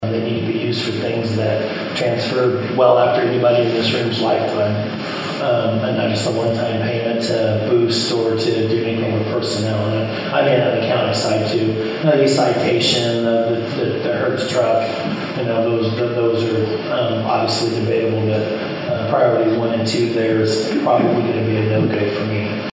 Board member and Riley County Commission Chair John Ford said, however, it’s not likely the personnel related items will get his vote when debated by that panel.